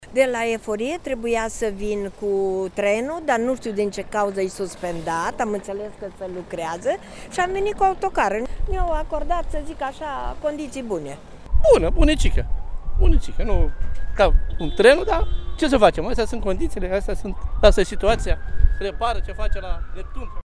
Iată ce spun călătorii: